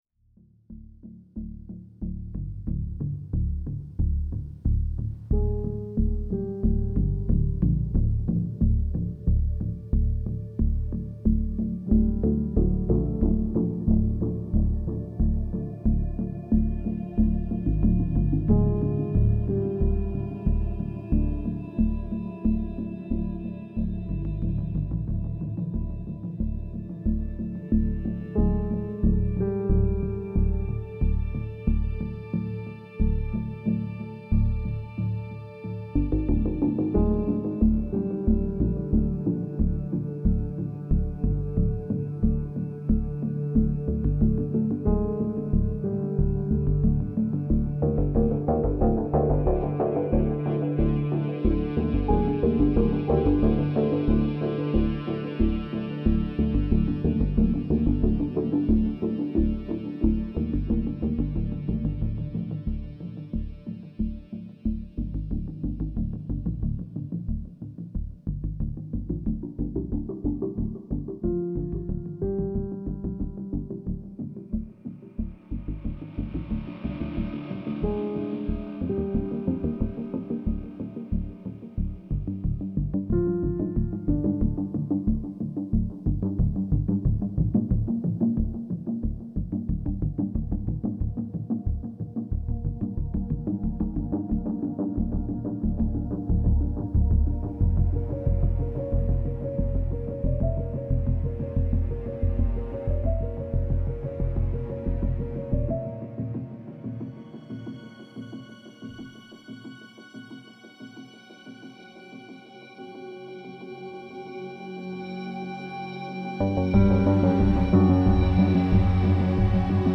Thriller - Tension